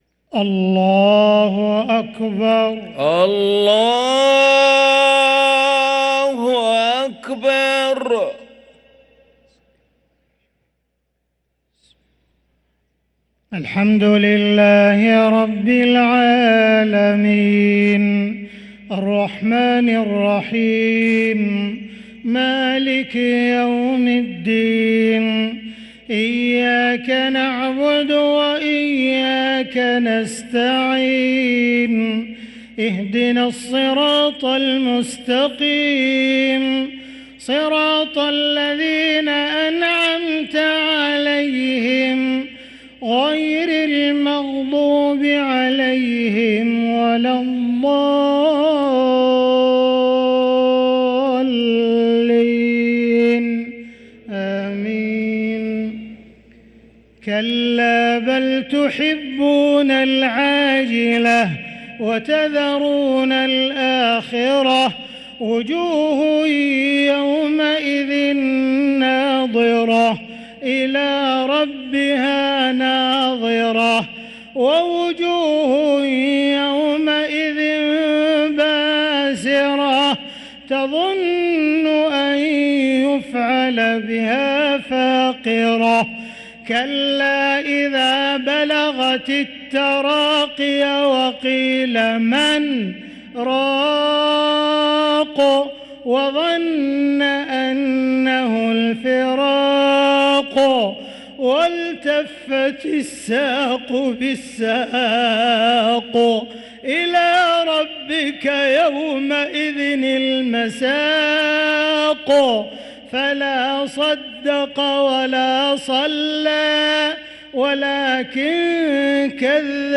صلاة المغرب للقارئ عبدالرحمن السديس 9 رجب 1445 هـ